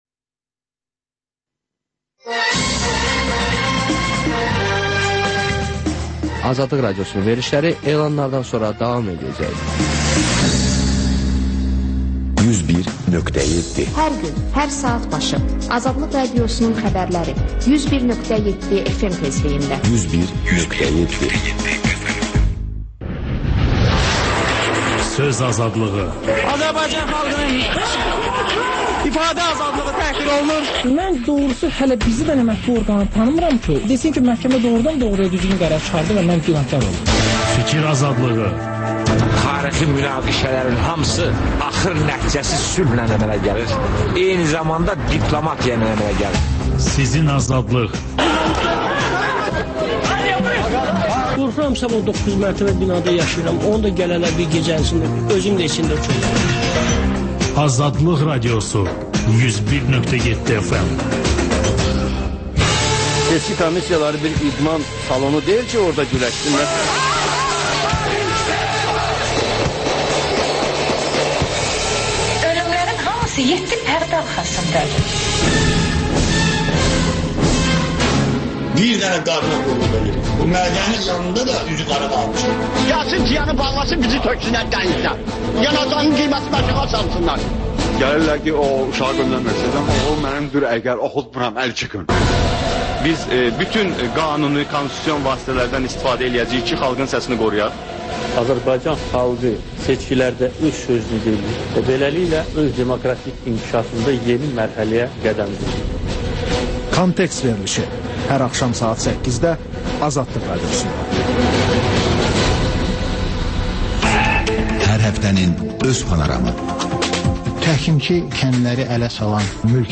Xəbərlər, İZ: Mədəniyyət proqramı və TANINMIŞLAR verilişi: Ölkənin tanınmış simalarıyla söhbət